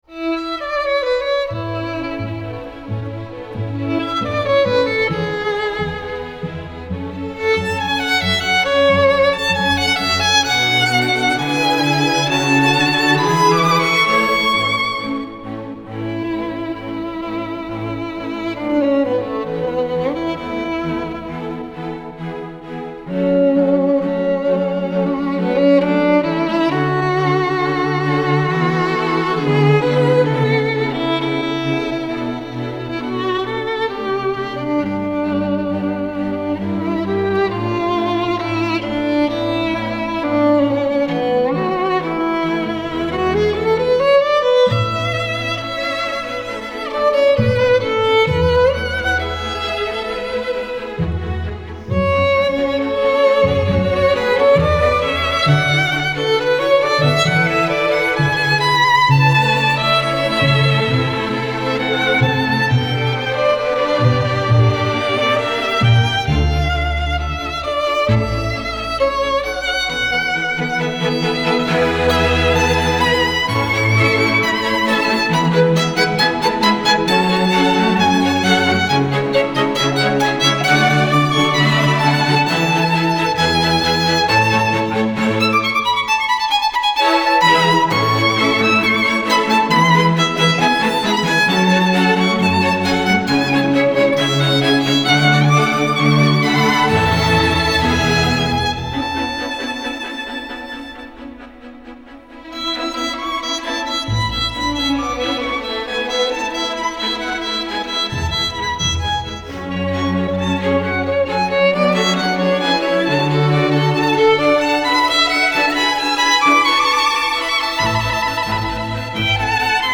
Вот как здесь, например)))  (Немножко долго искала, извини, забыла,где она у меня)  Да, начало грустноватое, но зато ж развитие какое!